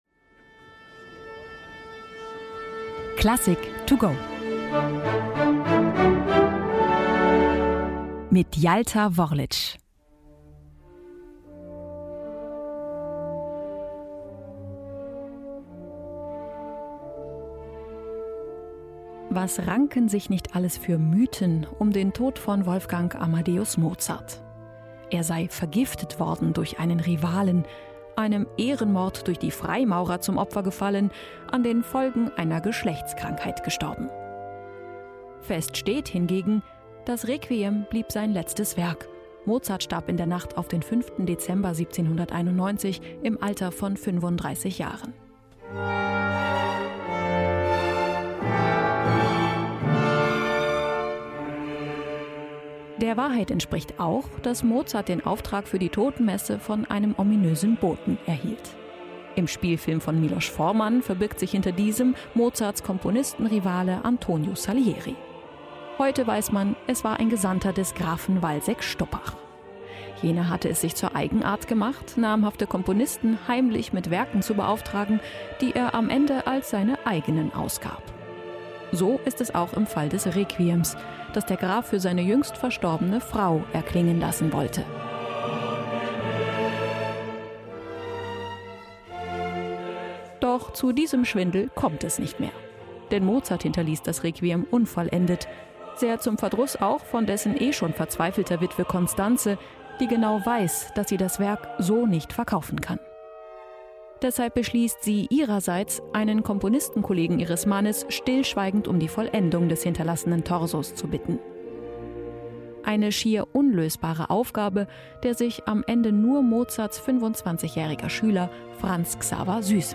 Eine Werkeinführung